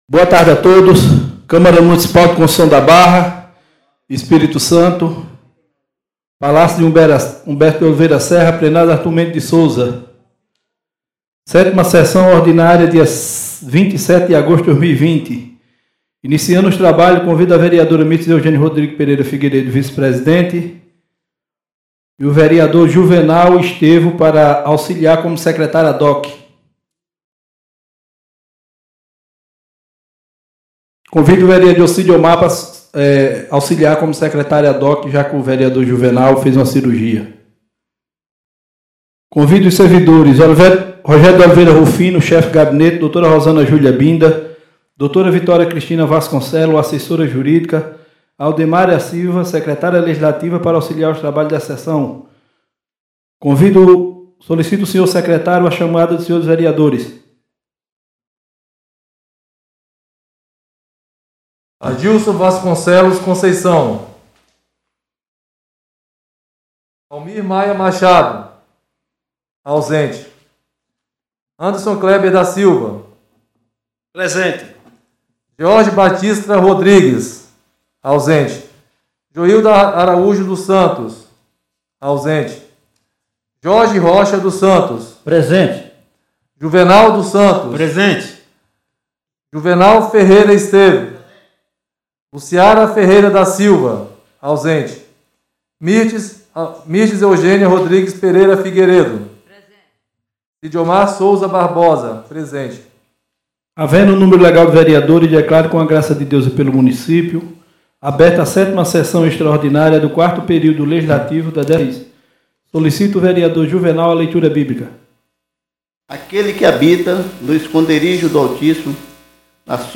7ª Sessão Extraordinária do dia 27 de agosto de 2020